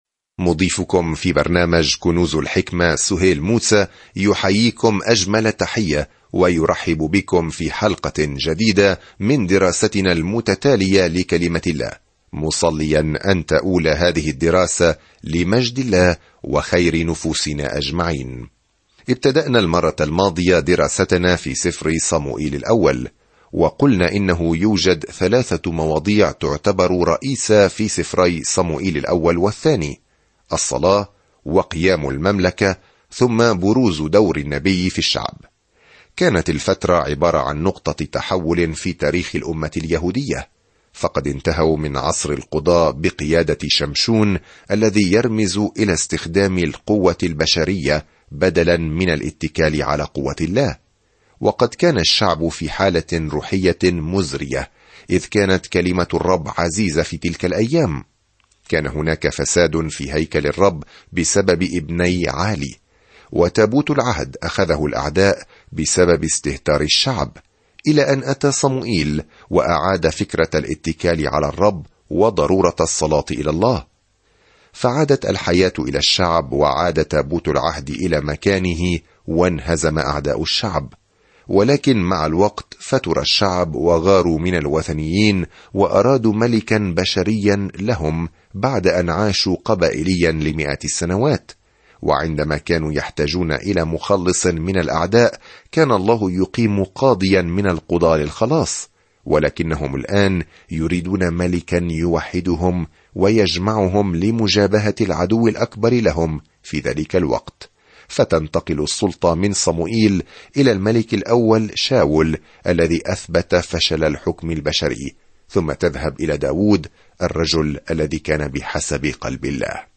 الكلمة صَمُوئِيلَ ٱلْأَوَّلُ 2 يوم 1 ابدأ هذه الخطة يوم 3 عن هذه الخطة يبدأ صموئيل الأول بالله كملك لإسرائيل ويستمر في قصة كيف أصبح شاول ثم داود ملكًا. سافر يوميًا عبر صموئيل الأول وأنت تستمع إلى الدراسة الصوتية وتقرأ آيات مختارة من كلمة الله.